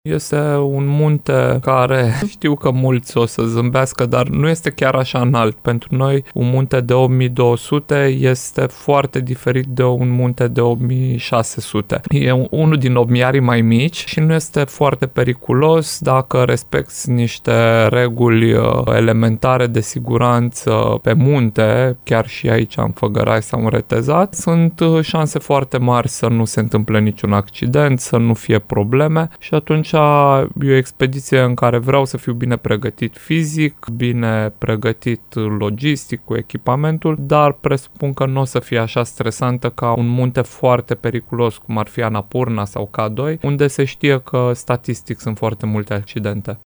Cel puțin statistic, va fi o expediție mai stabilă, a spus Horia Colibășanu în cadrul emisiunii ”De la Om la Om” de la Radio Timișoara.